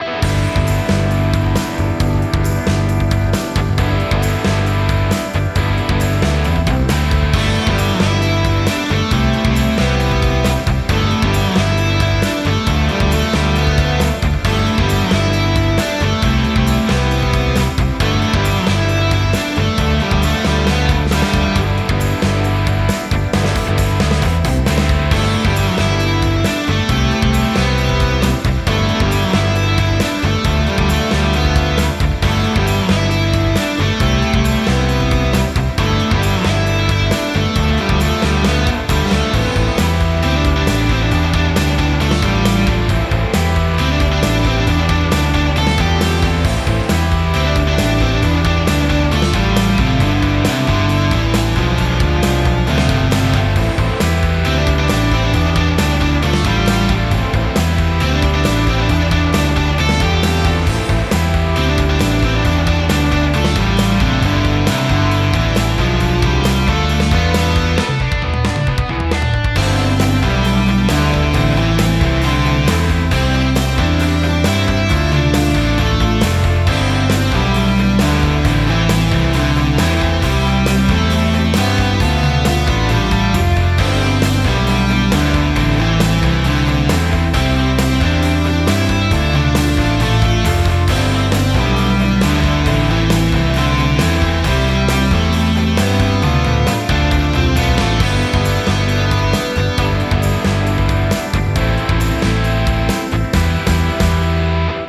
Electric guitar music for field theme.